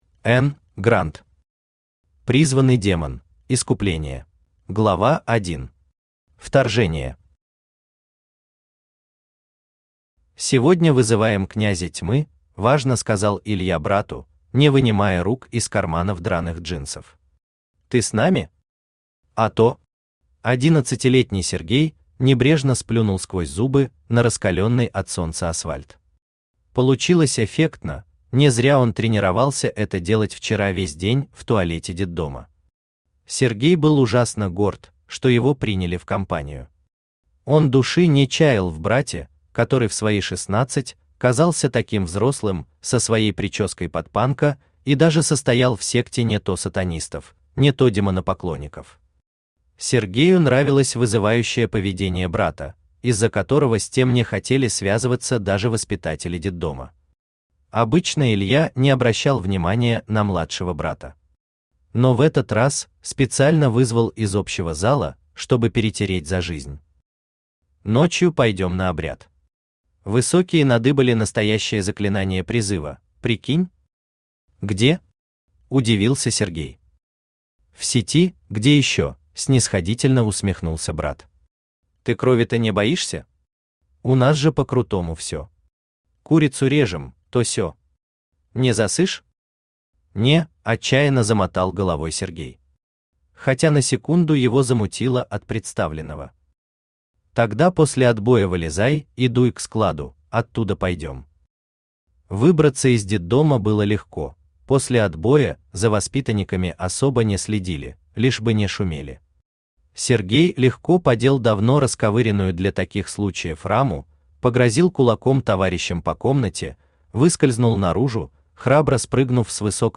Aудиокнига Призванный демон: искупление Автор Н. Гранд Читает аудиокнигу Авточтец ЛитРес.